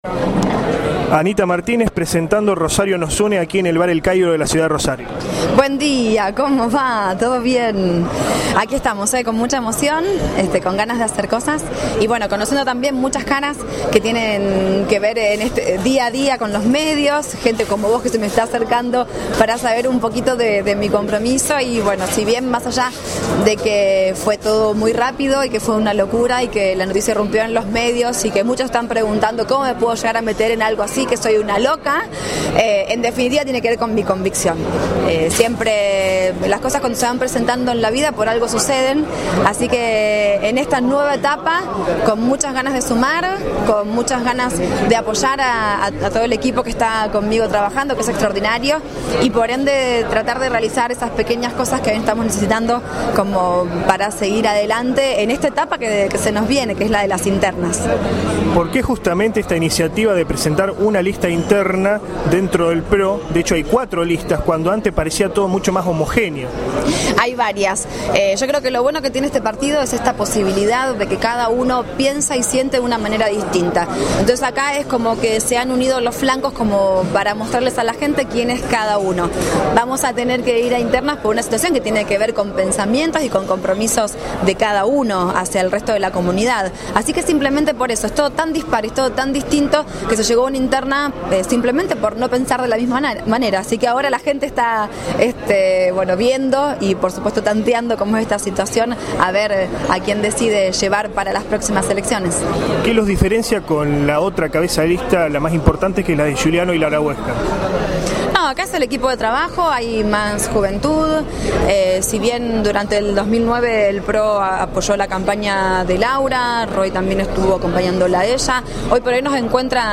AUDIO ENTREVISTA
Cuna de la Noticia estuvo presente en el lanzamiento de la lista «Rosario Nos Une», llevado a cabo el día de ayer en el tradicional Bar «El Cairo» de la ciudad de Rosario.